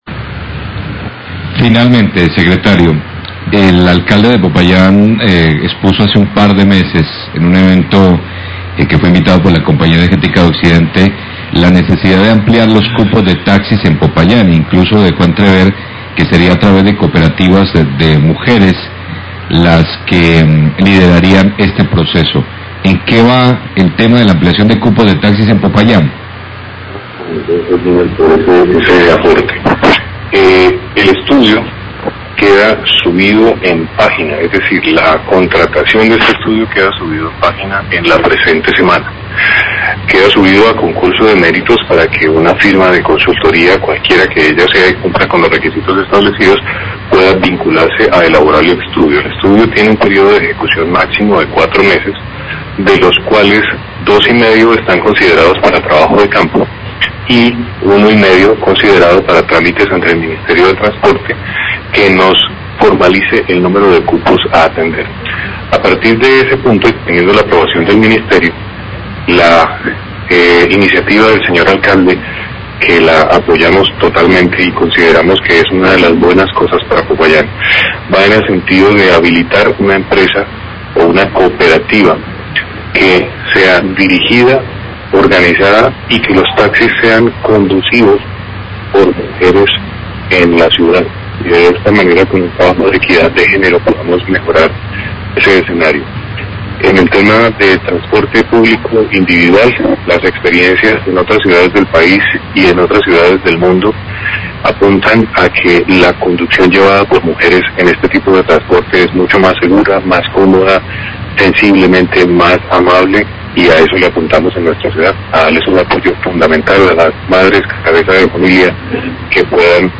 Radio
El Secretario de Movilidad de Popayán habla de la propuesta para ampliar los cupos de taxi a través de una cooperativa liderada por mujeres cabeza de familia, idea que fue presentada durante un evento de la Compañía Energética.